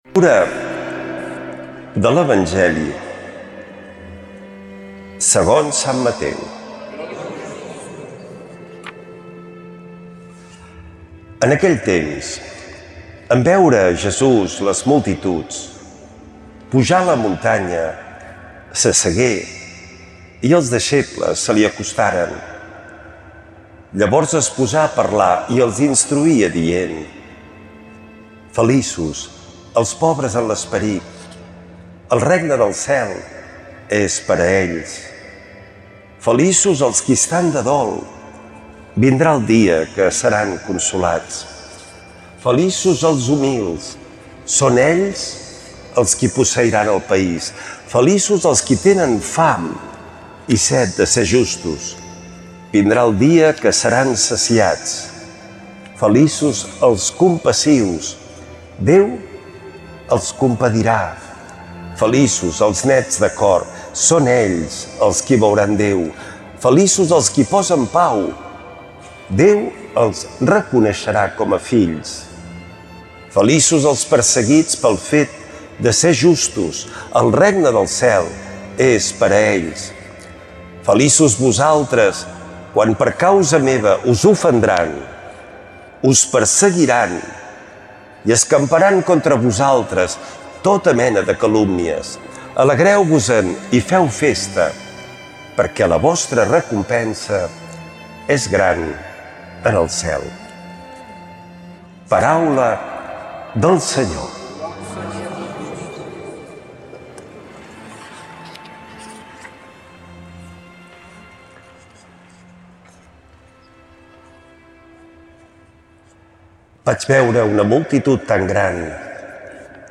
L’Evangeli i el comentari de dissabte 01 de novembre del 2025.